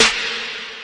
T.I.YYK Snare.wav